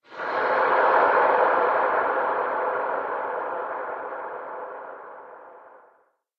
cave4.ogg